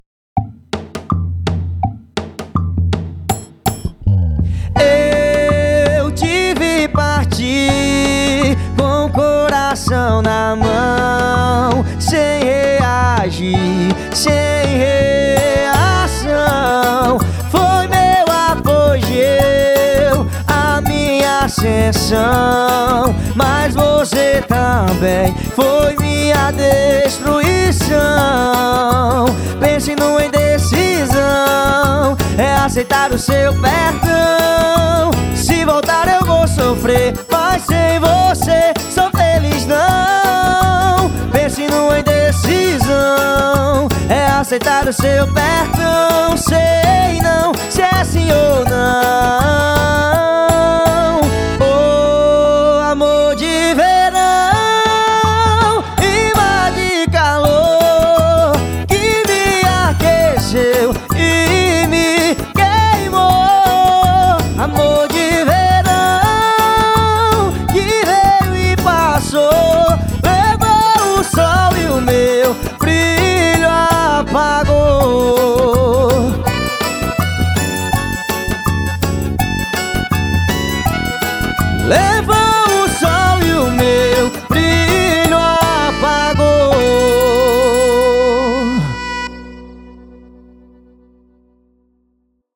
FORRÓ